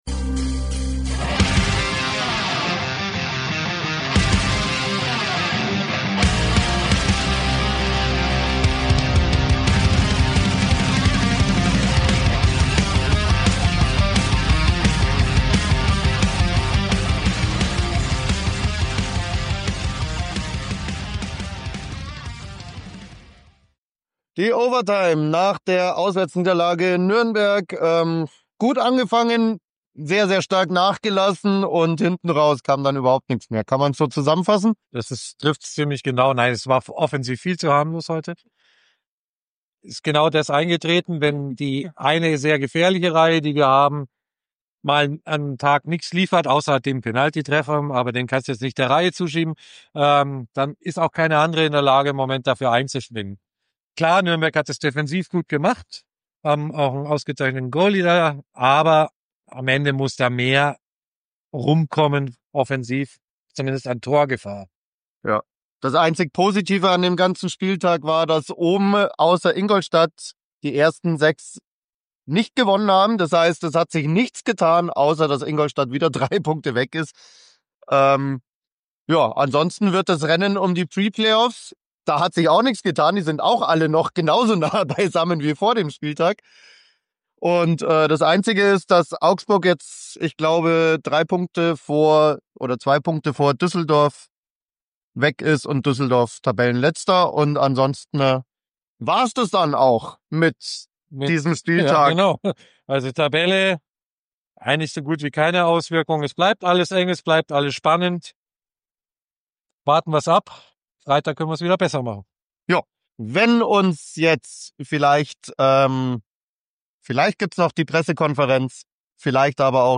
Spielzusammenfassung und Stimmen
Intro und Outro sind von der Band Viper Queen!